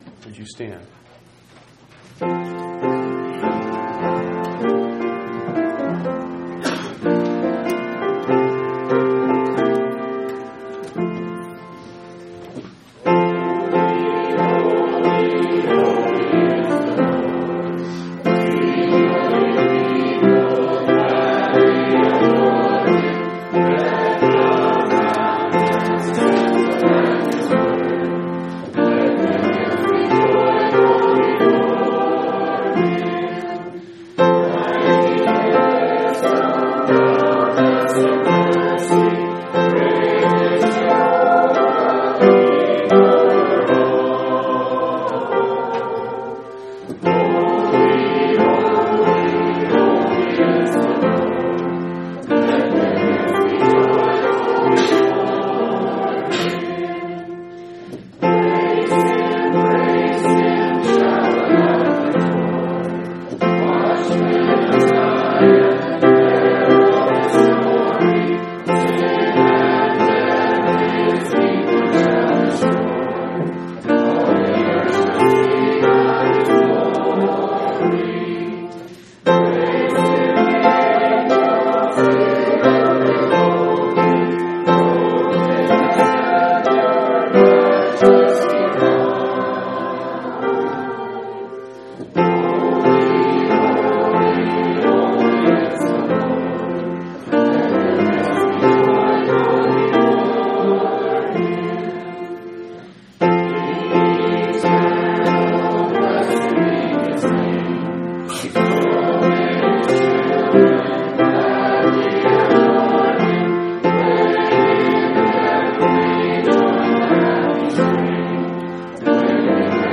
1/25/2004 Location: Phoenix Local Event